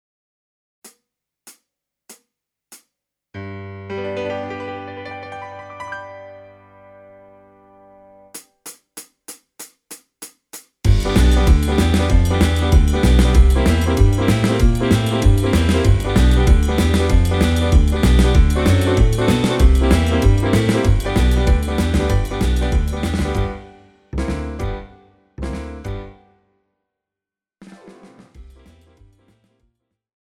Žánr: Rock And Roll
BPM: 96
Key: G
MP3 ukázka